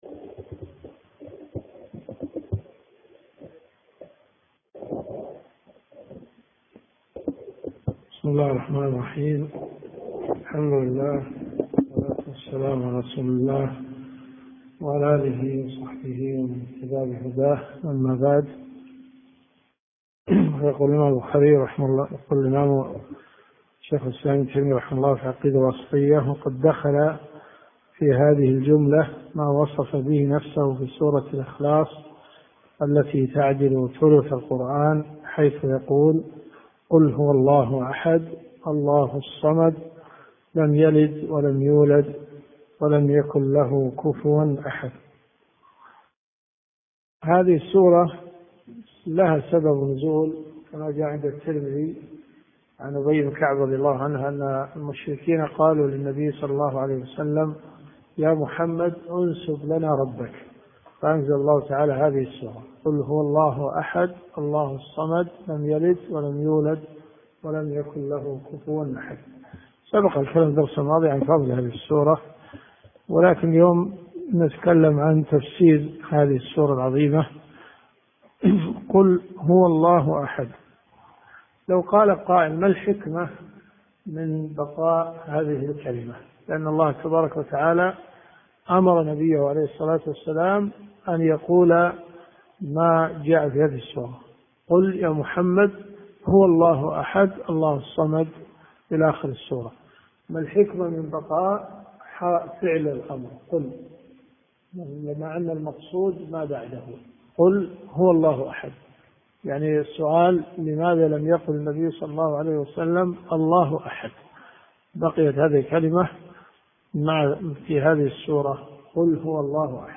الدروس الشرعية
دروس صوتيه ومرئية تقام في جامع الحمدان بالرياض